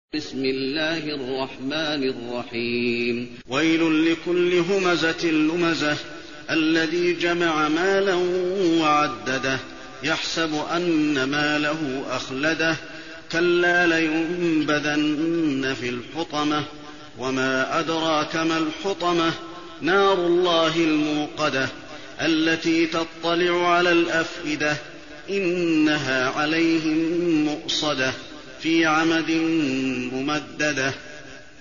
المكان: المسجد النبوي الهمزة